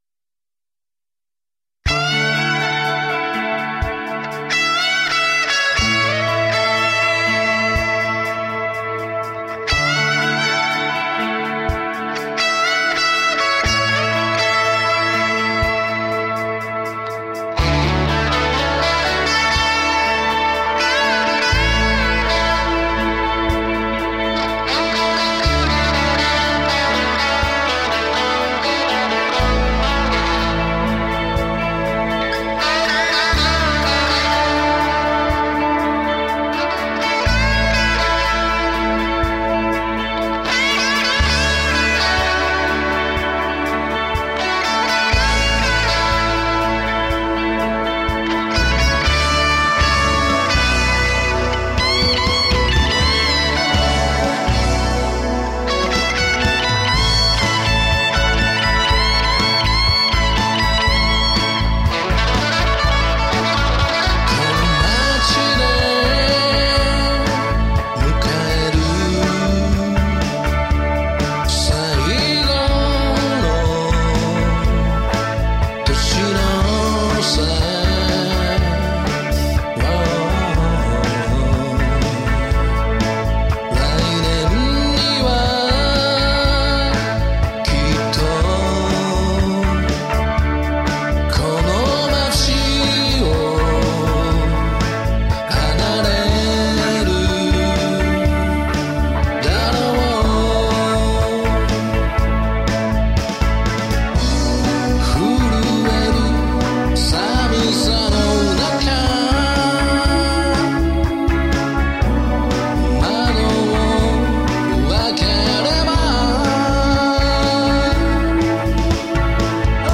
オリジナルソングにTD-3で叩いたものがあります。但し、TD3で叩いたものを　Cubaseで録りクオンタイズ掛けています。